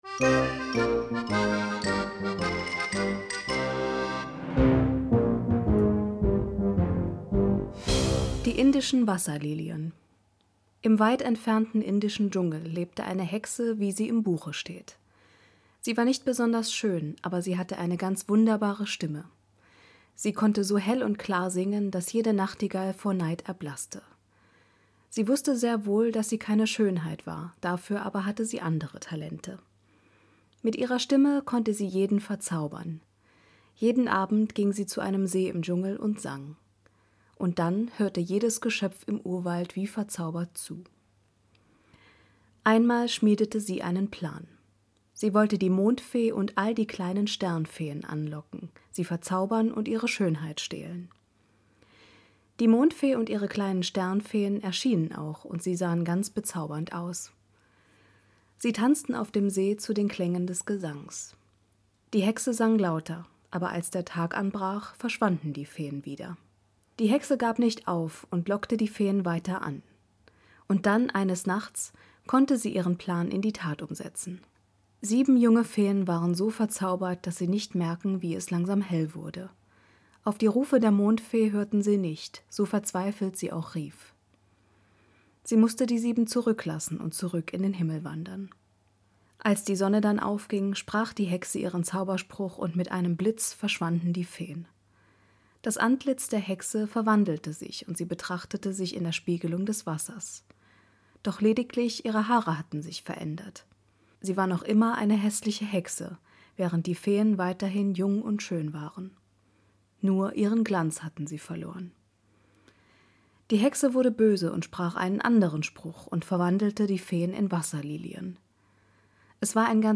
Dieses Märchen wurde von mir nacherzählt.